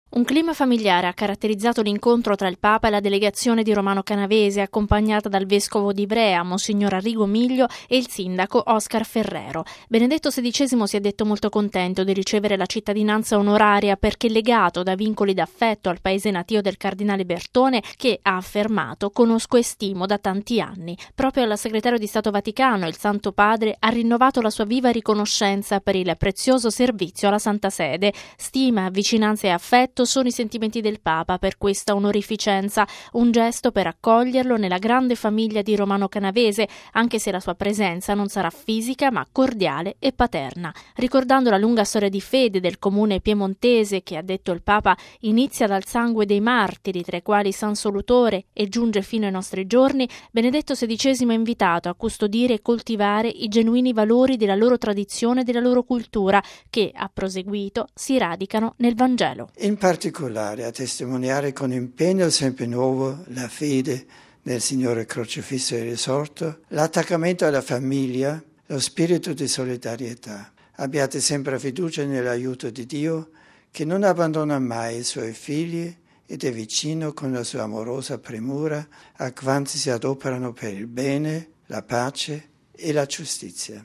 ◊   Al termine dell’udienza generale, il Papa ha ricevuto, nell'auletta dell'Aula Paolo VI, una delegazione di Romano Canavese, il comune della provincia di Torino e in diocesi d'Ivrea che ha dato i natali al cardinale segretario di Stato Tarcisio Bertone.